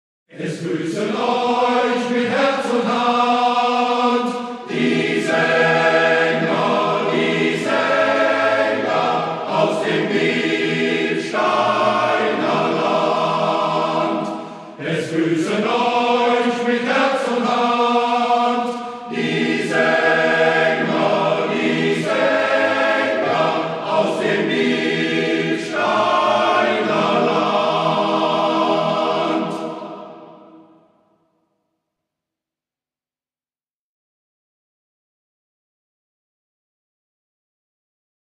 Der Sängergruß des Bielsteiner Männerchors